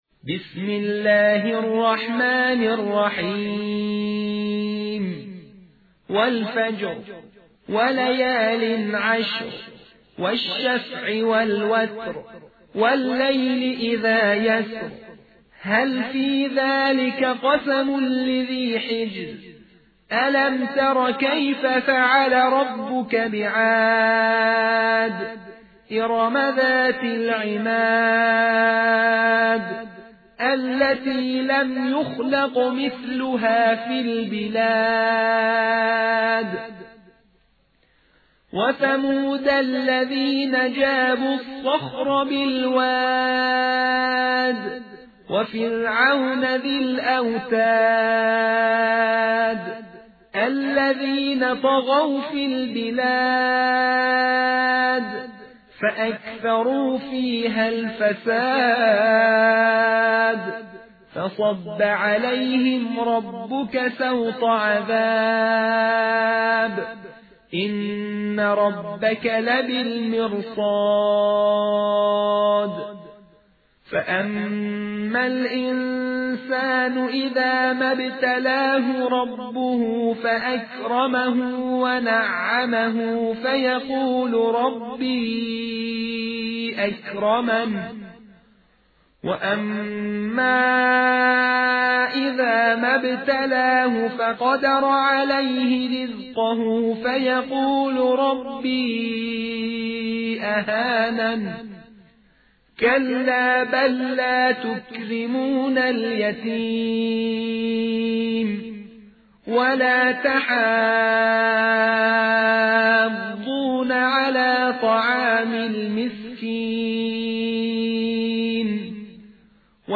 ایکنا در ماه محرم و در ایام عزاداری سید و سالار شهیدان حضرت اباعبدالله الحسین(ع)، تلاوت سوره مبارکه فجر را با صوت قاریان مشهور جهان، بین‌المللی و ممتاز کشور منتشر می‌کند.
تلاوت ترتیل